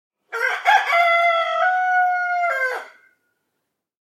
Rooster Crowing Alram